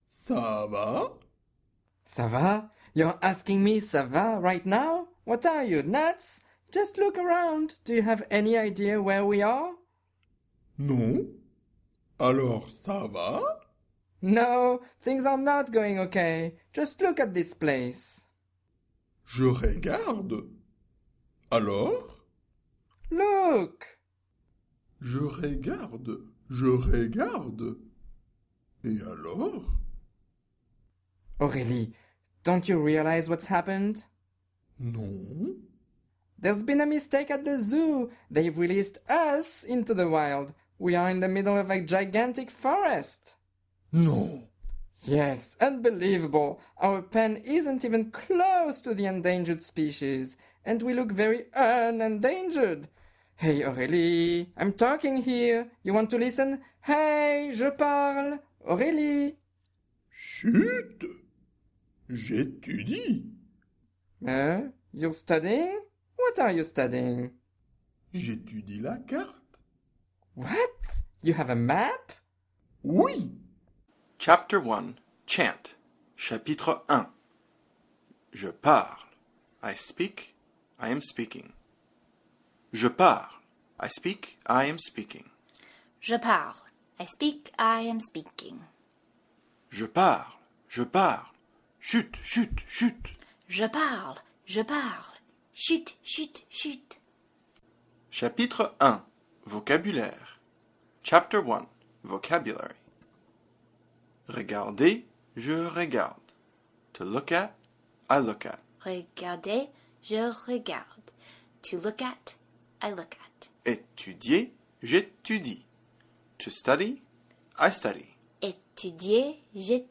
The files feature all of the pronunciations from the Pronunciation Wizard as well as the dialogues, grammar chart chants, complete vocabulary, Conversation Journal words and phrases, Say It Aloud exercises, dicteés, and more!